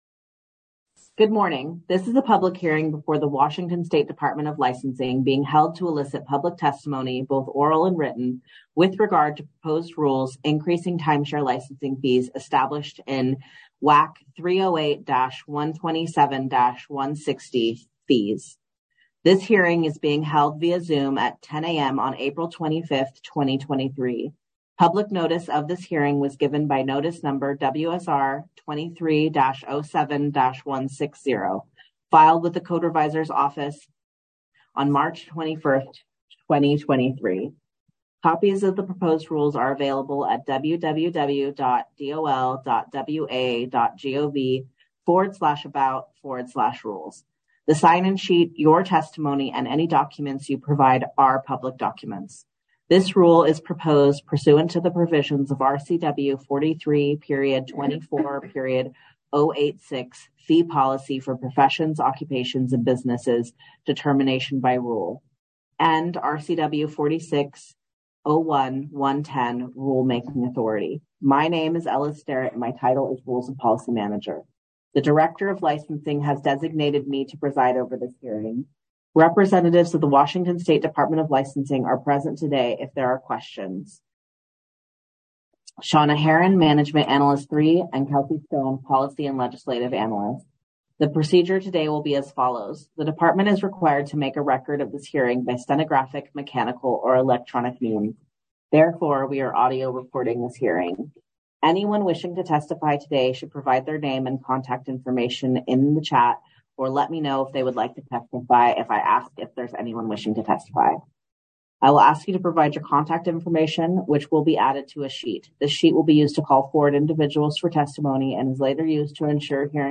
Public hearing or comments
Public hearing held April 25, 2023